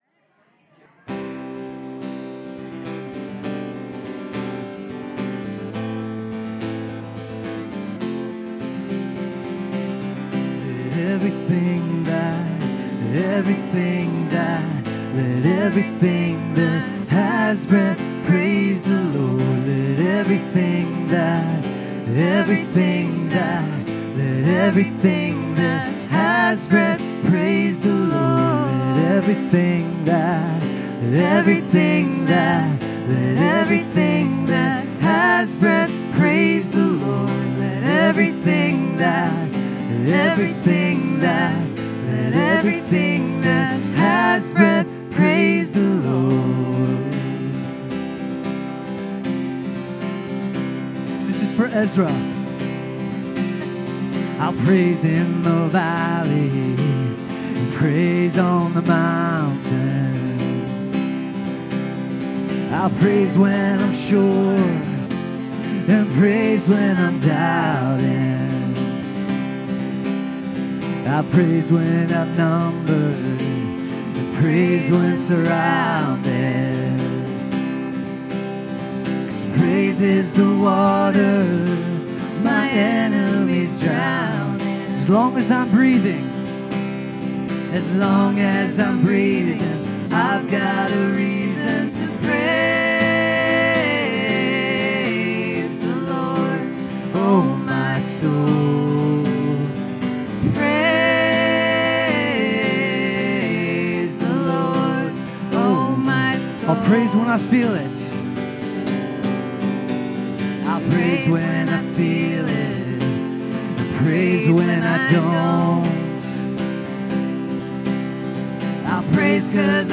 Worship 2025-10-26